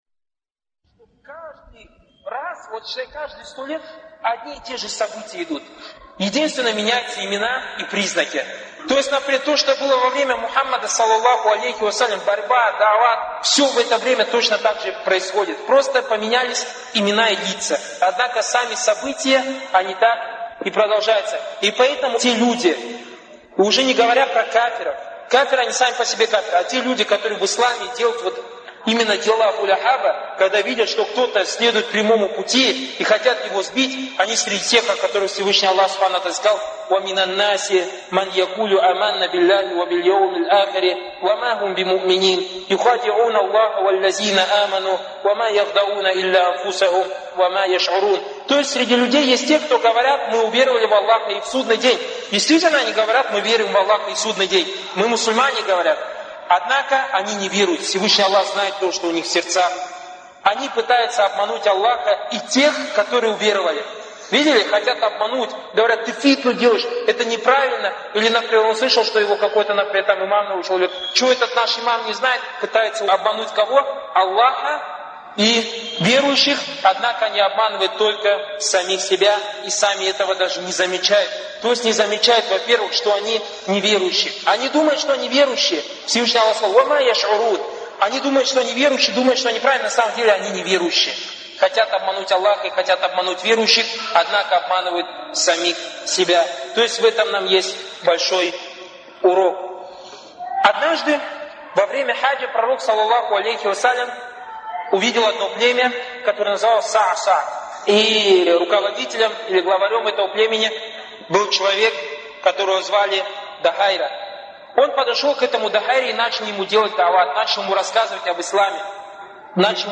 лекции Торик Суейдана (были приняты во внимание его ошибки, на которые указали учёные).